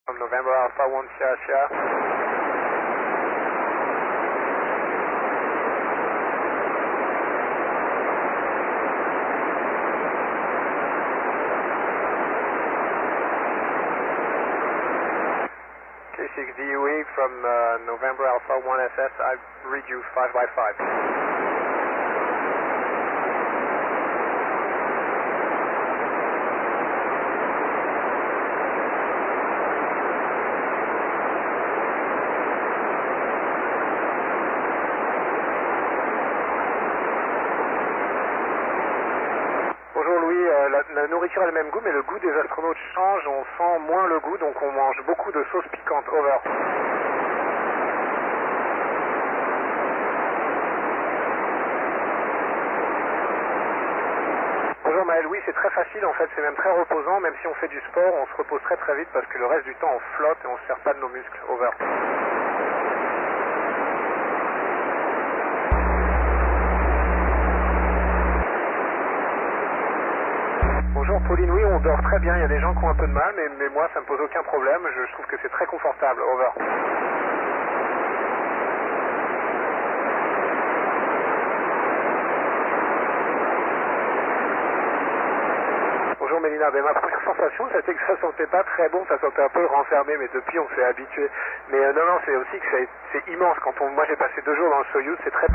I guess I have some feedback on recording from the antenna controller.....I'll have to fix that....
Recorded Contact